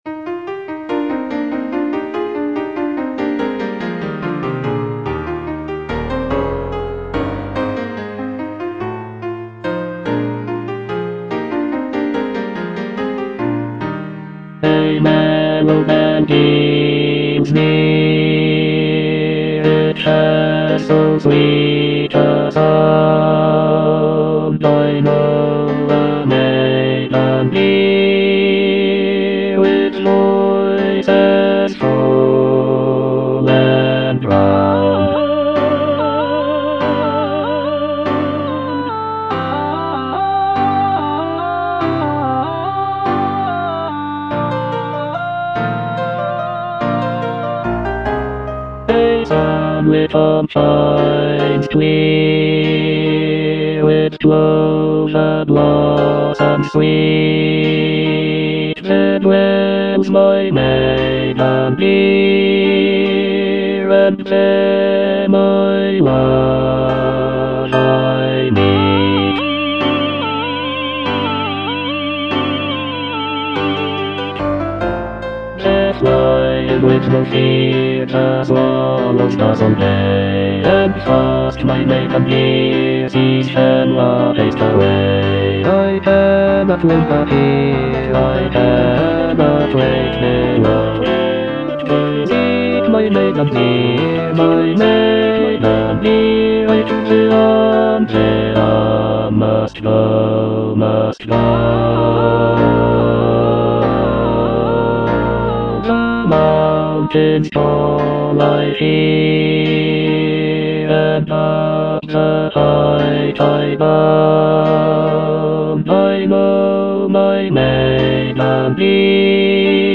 E. ELGAR - FROM THE BAVARIAN HIGHLANDS On the alm (bass II) (Emphasised voice and other voices) Ads stop: auto-stop
The piece consists of six choral songs, each inspired by Elgar's travels in the Bavarian region of Germany. The music captures the essence of the picturesque landscapes and folk traditions of the area, with lively melodies and lush harmonies.